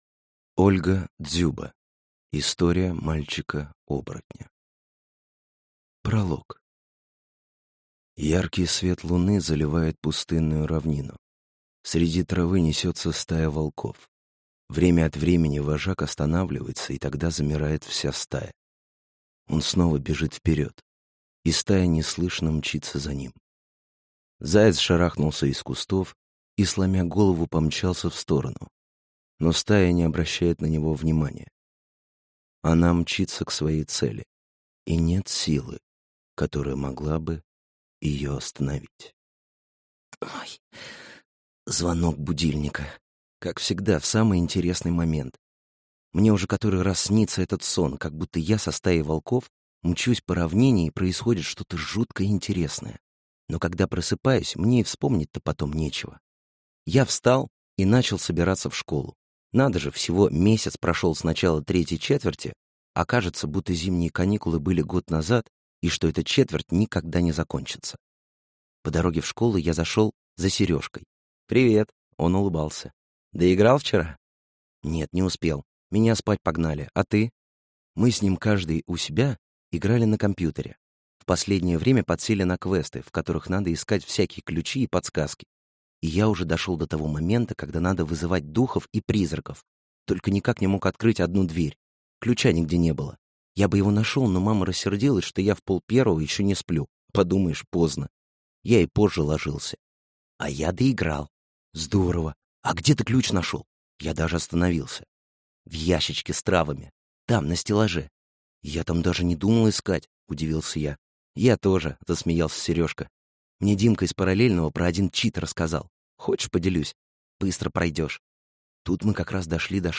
Аудиокнига История мальчика-оборотня | Библиотека аудиокниг
Прослушать и бесплатно скачать фрагмент аудиокниги